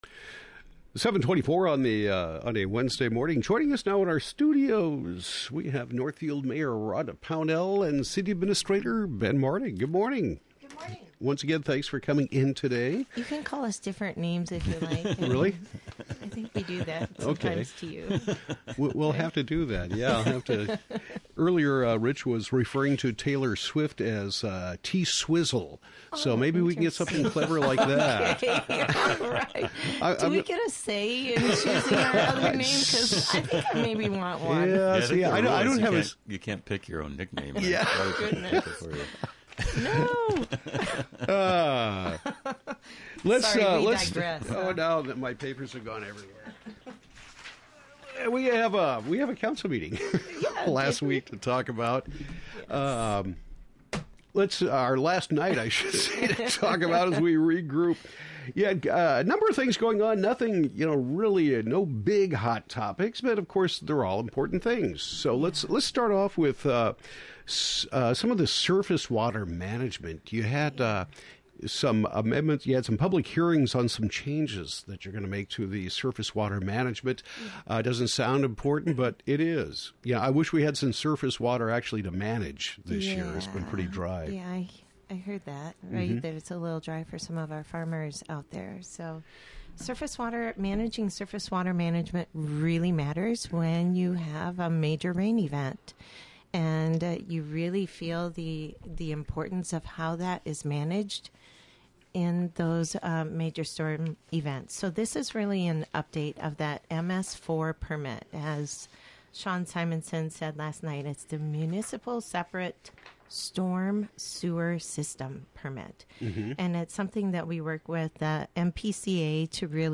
Northfield Mayor Rhonda Pownell and City Administrator Ben Martig discuss the October 18 City Council meeting. Topics include surface water management, clean energy, and plans for Riverwalk Market to use Northfield News building during winter season.